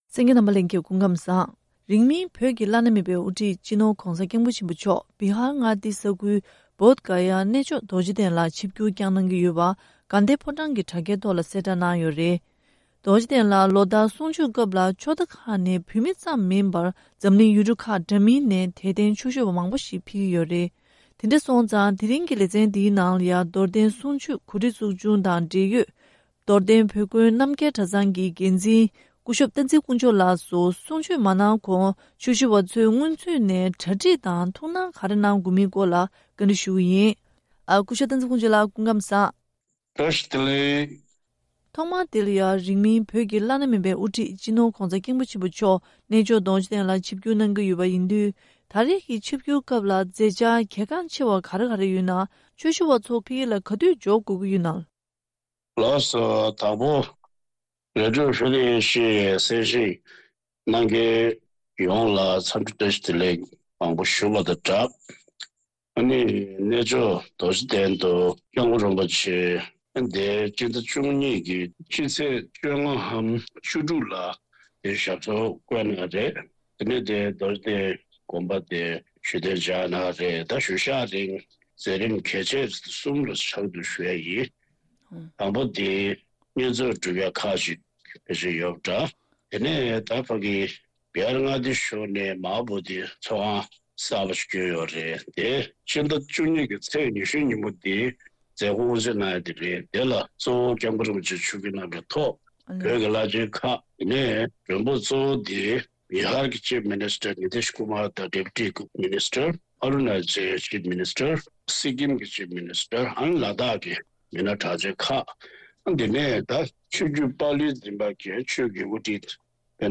བཅར་འདྲིའི་ལེ་ཚན